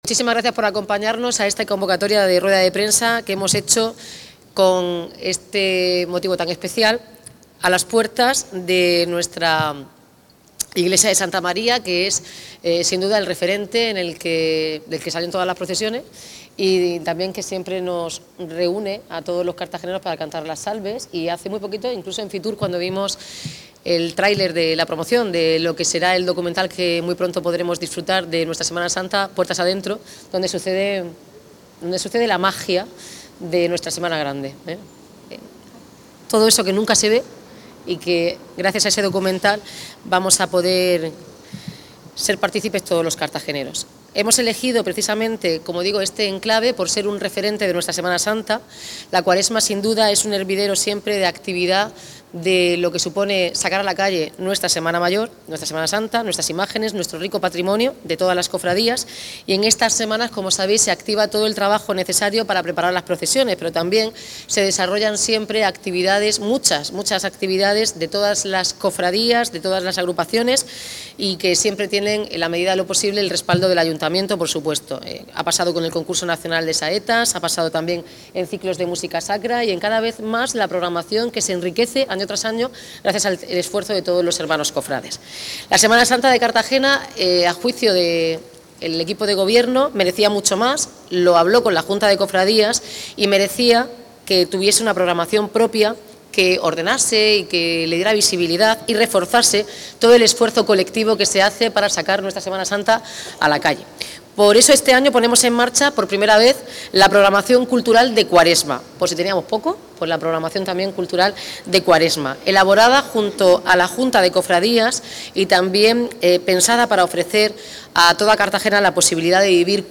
Enlace a Declaraciones de la alcaldesa Noelia Arroyo
El acto tuvo lugar a las puertas de la Iglesia de Santa María de Gracia, un lugar emblemático para la Semana Santa cartagenera.